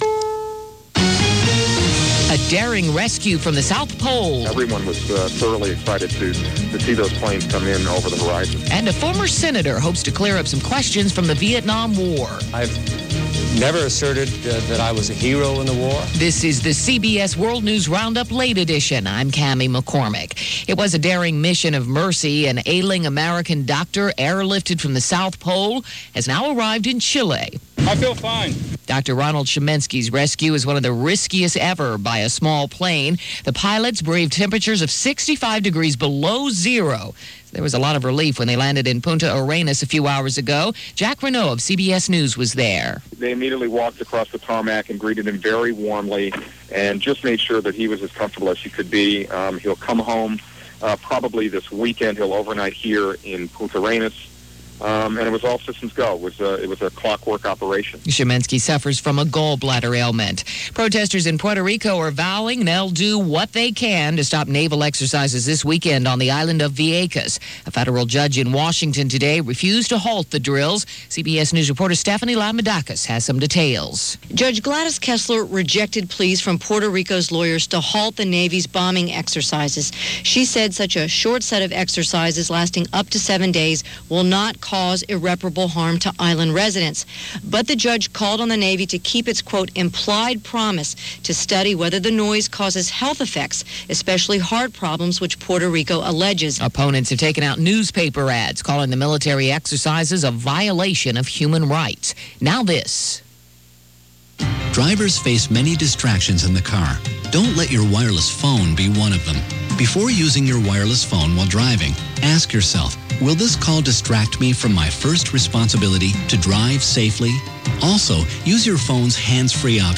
And that’s just some of what happened, this April 26. 2001 – as reported by The CBS World News Roundup: Late Edition.